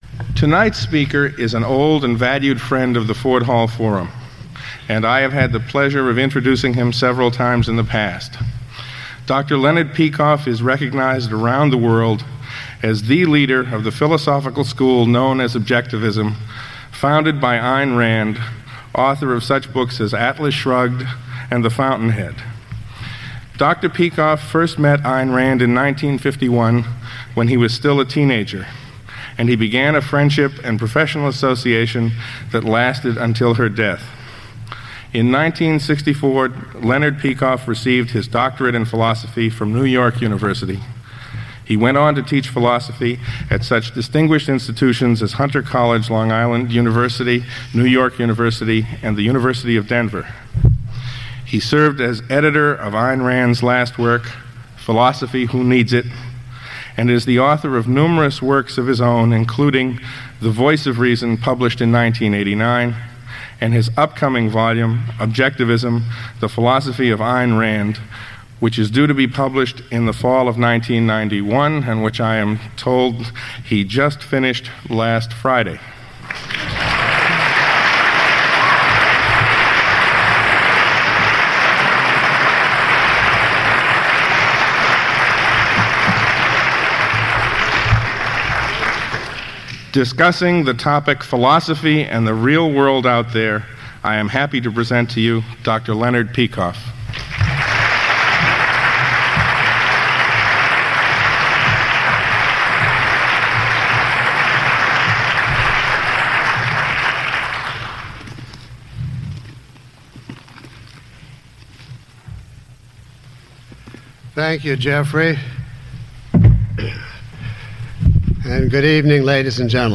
Lecture (MP3) Questions about this audio?
Below is a list of questions from the audience taken from this lecture, along with (approximate) time stamps.